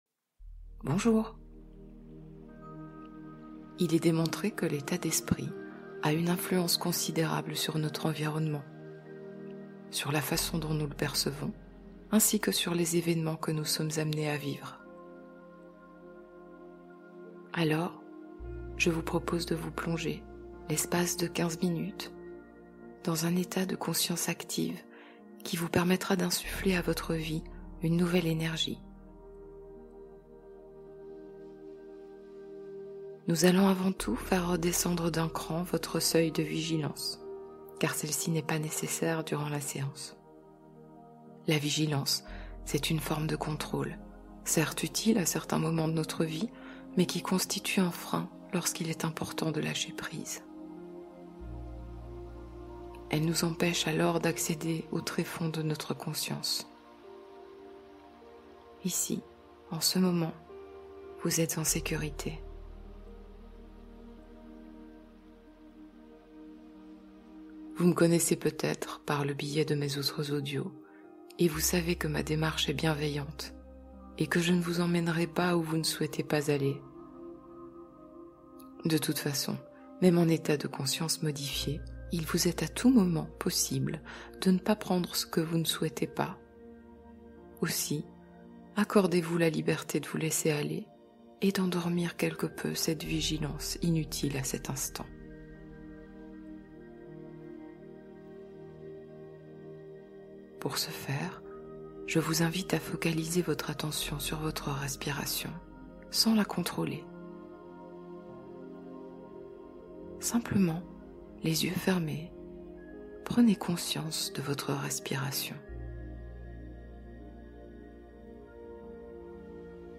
Attirer l’amour : hypnose pour ouvrir le cœur